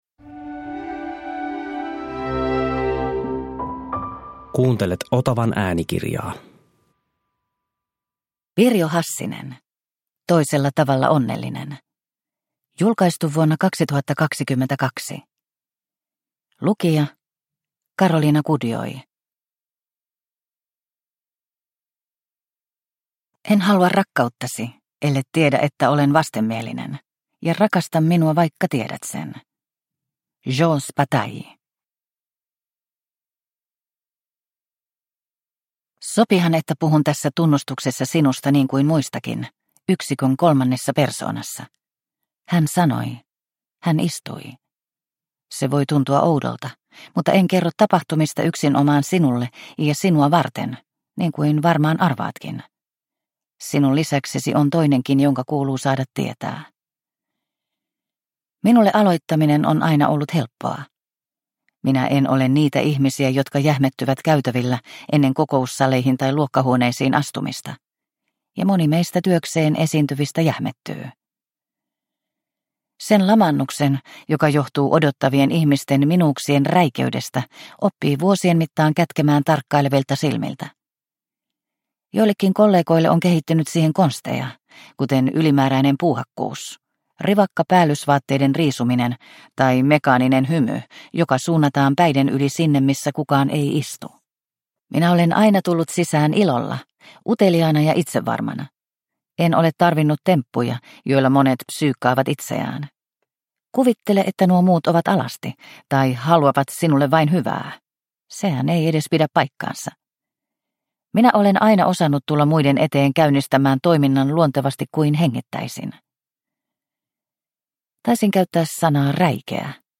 Toisella tavalla onnellinen – Ljudbok – Laddas ner